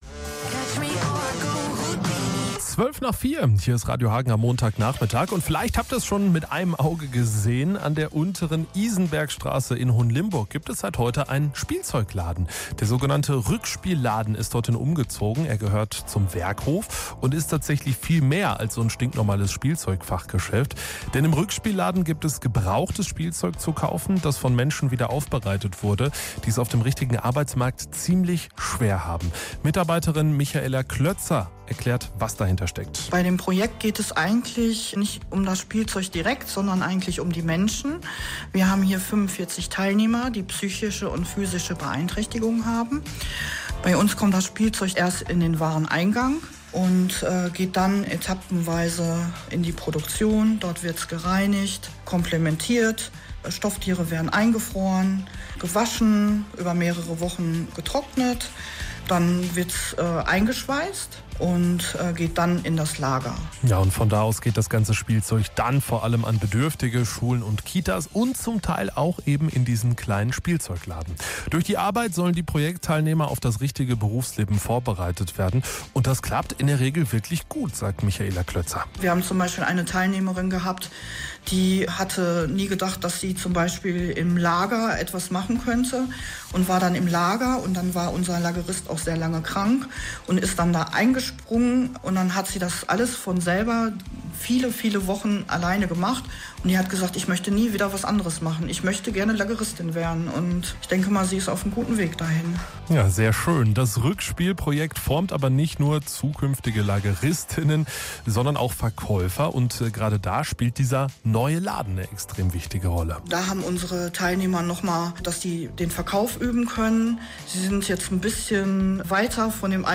Die zwei Radiobeiträge könnt ihr unten nochmal anhören und gerne auch herunterladen.
sendungsmitschnitt-rueckspiel-laden.mp3